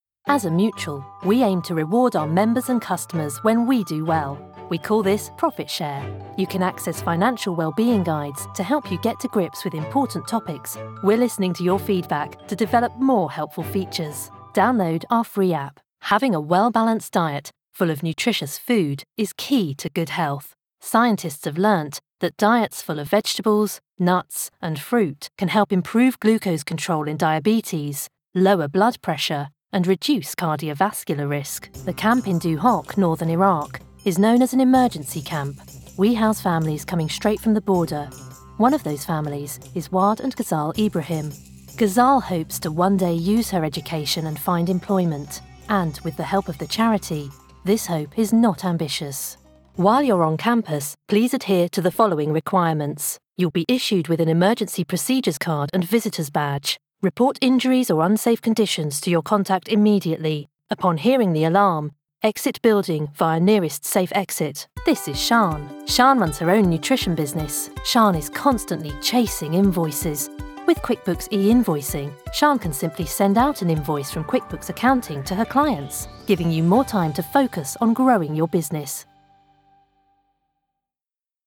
Vídeos Corporativos
Meu sotaque é britânico neutro, com tons RP e londrinos que podem ser naturalmente formais ou informais, dependendo do estilo necessário.
Tenho um estúdio de gravação doméstico construído para esse fim, o que significa que posso produzir áudio com qualidade de transmissão rapidamente e a um preço competitivo.
Microfone: Rode NT1
Jovem adulto
Meia-idade